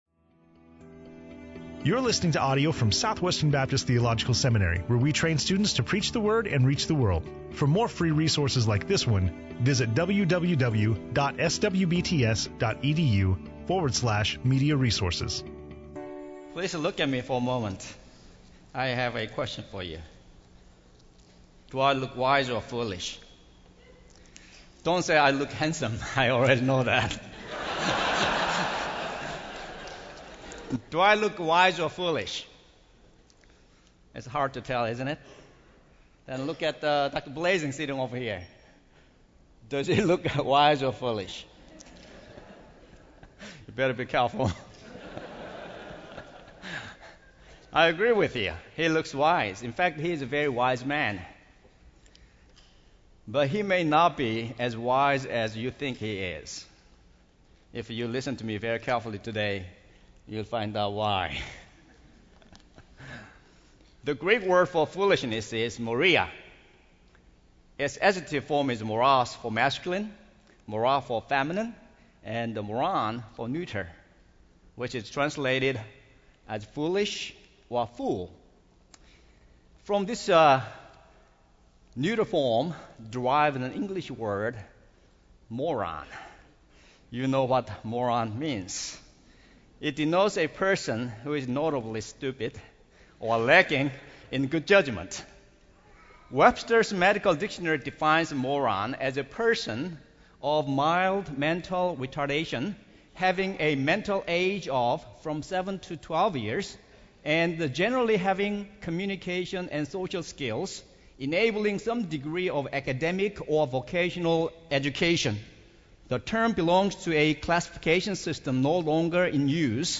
SWBTS Chapel Audio